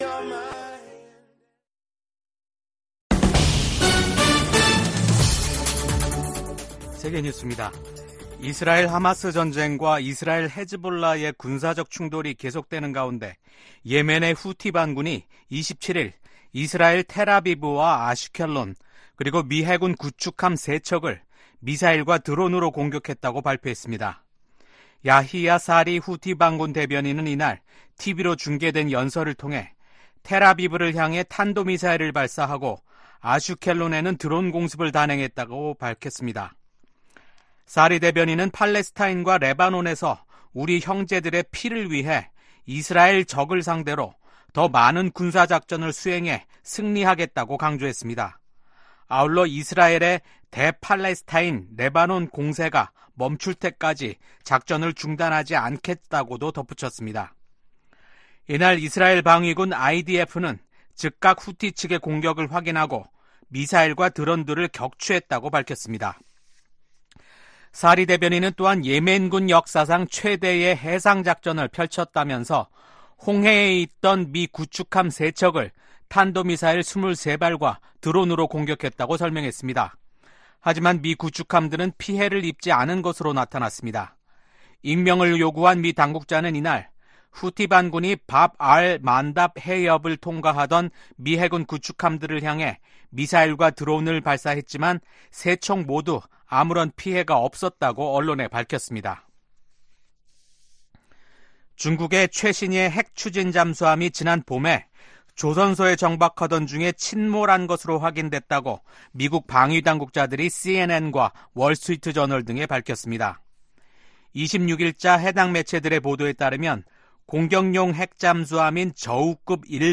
VOA 한국어 아침 뉴스 프로그램 '워싱턴 뉴스 광장' 2024년 9월 28일 방송입니다. 미국 대북 정책의 주요 요소는 종교와 신앙의 자유에 대한 정보를 포함한 북한 내 정보 접근을 확대하는 것이라고 미국 북한인권특사가 밝혔습니다. 미국과 영국, 호주의 안보협의체인 오커스가 첨단 군사기술 개발 협력 분야에서 한국 등의 참여 가능성을 논의 중이라고 확인했습니다.